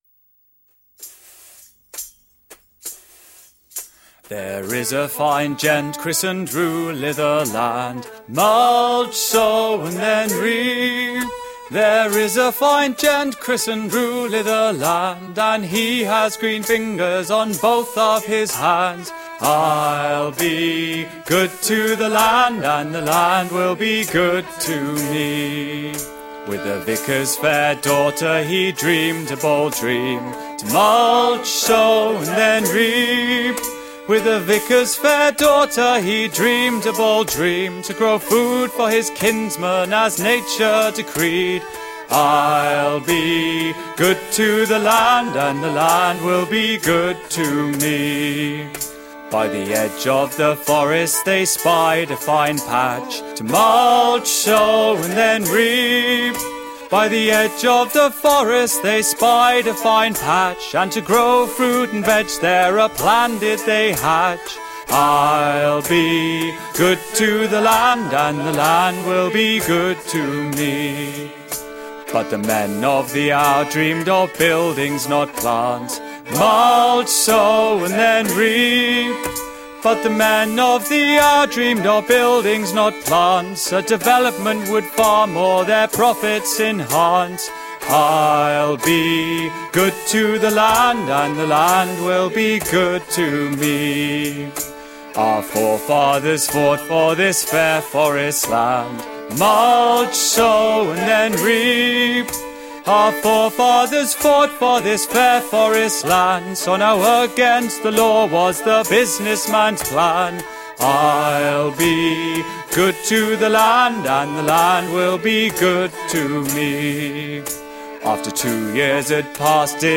Gently experimental nu-folk.
lush instrumentation
Tagged as: Alt Rock, Folk-Rock, Folk